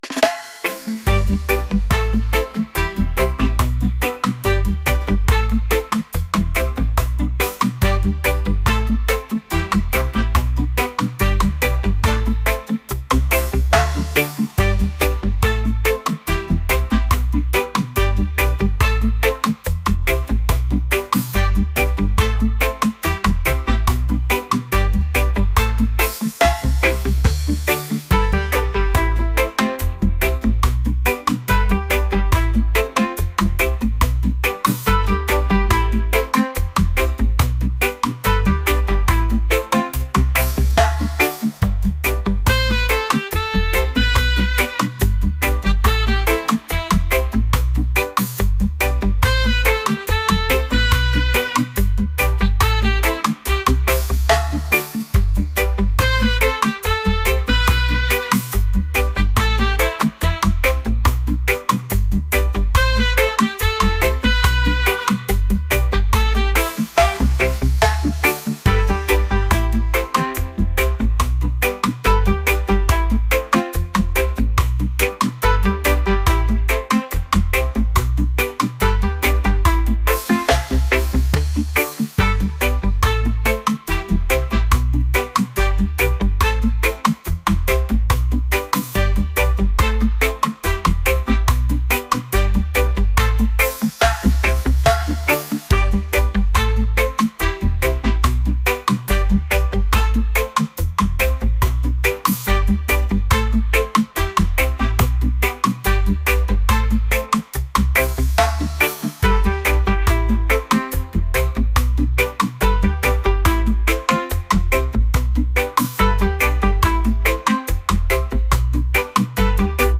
reggae | pop | folk